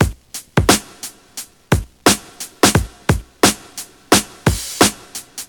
• 87 Bpm 2000s Hip-Hop Drum Loop Sample G Key.wav
Free drum loop - kick tuned to the G note. Loudest frequency: 3188Hz